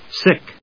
/sík(米国英語)/